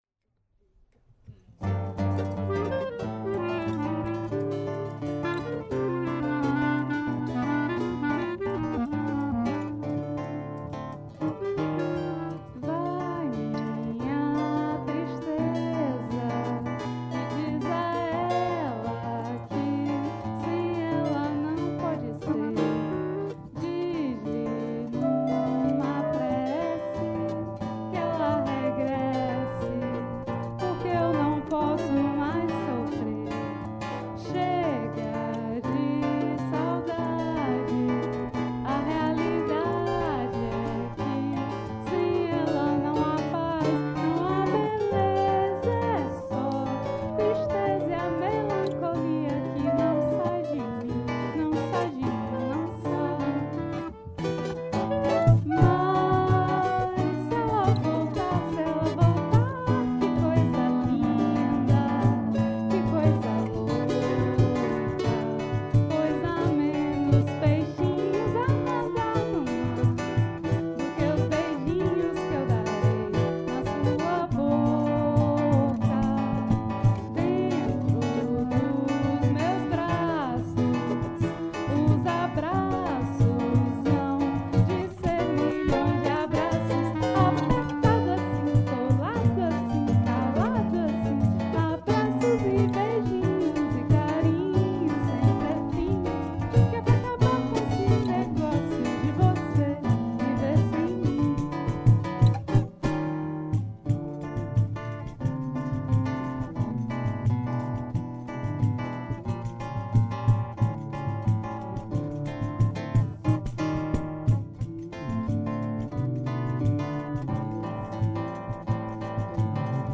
Joué pour la fête de la Musique 2024 au CE TAS